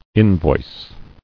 [in·voice]